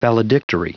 Prononciation du mot valedictory en anglais (fichier audio)
valedictory.wav